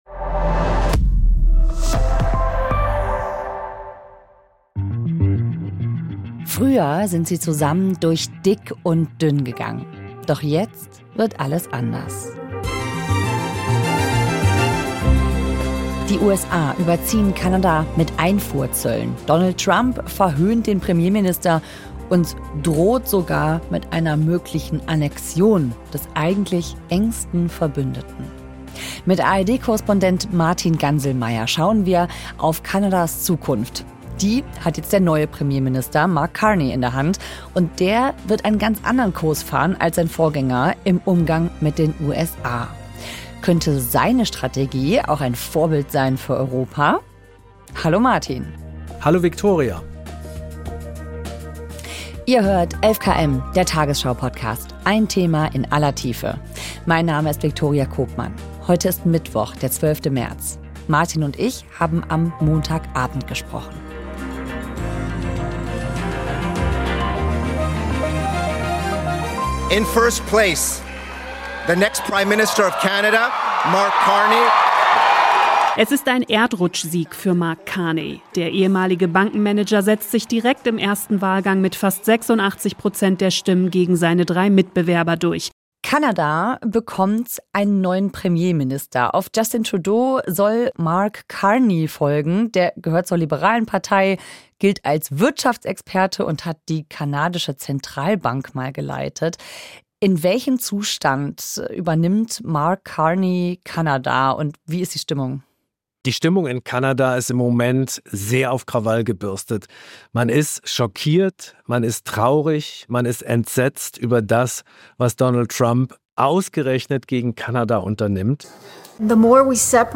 Könnte seine Strategie ein Vorbild sein für Europa? Hinweise: Das Gespräch fand am Montag (10.3.) um 18.30 Uhr statt.